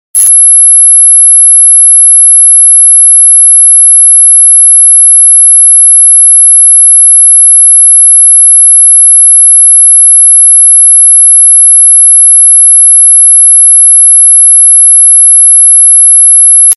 Ear Piercing Sound Bass Boosted sound effects free download